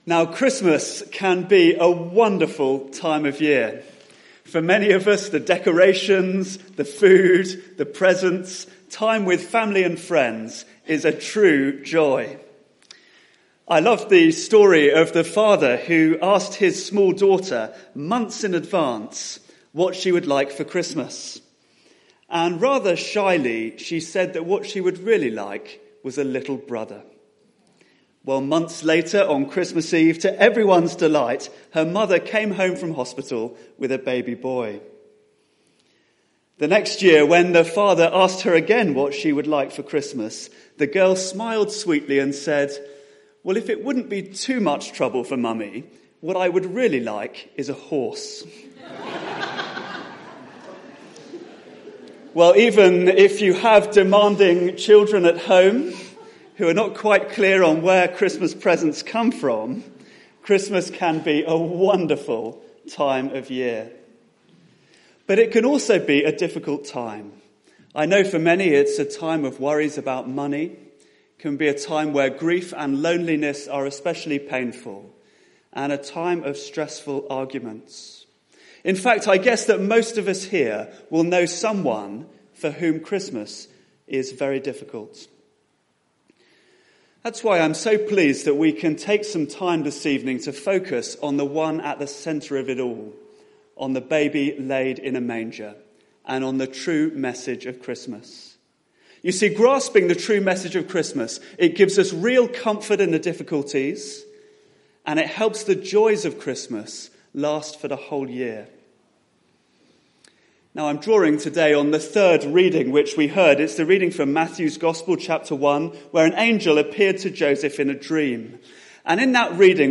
Passage: Matthew 1:18-25 Series: Christmas 2016 Theme: Sermon Search the media library There are recordings here going back several years.